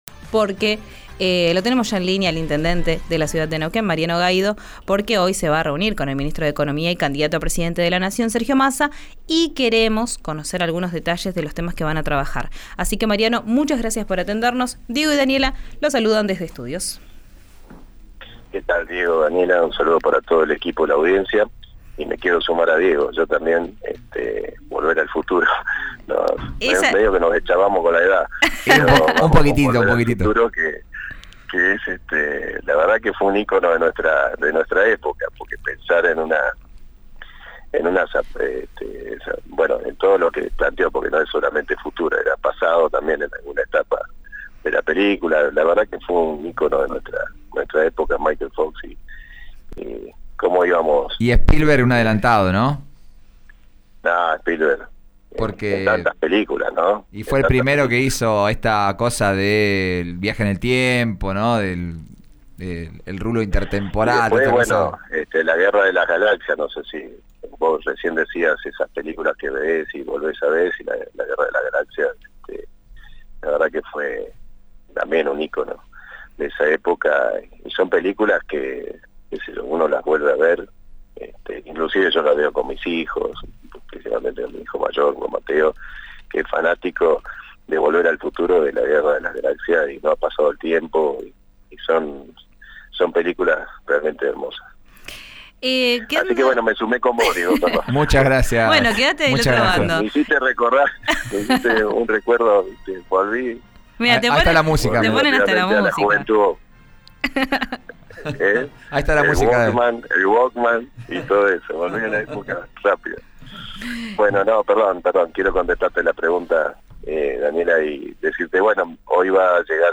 El intendente contó en RÍO NEGRO RADIO los detalles del encuentro de hoy a las 20 en casa de Gobierno.